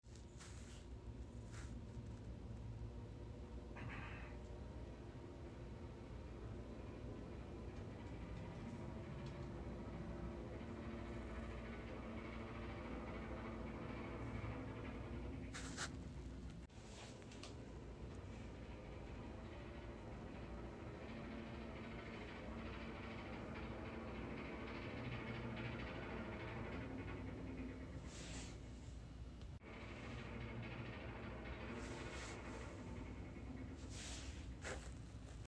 The hotel property is the Mandalay Bay Resort and Casino, Las Vegas, at which I had stayed in previous years.
The partially blocked view from the room was more than bad enough; but then a strange loud noise was heard every couple of minutes. The following sound file was recorded from the opposite side of the room from where the noise was emanating.
He concluded that not only would the noise continue unabated throughout the day and night; but the sound came from a nearby elevator which needed to be placed out of service and fixed.
Noises-in-Room.mp3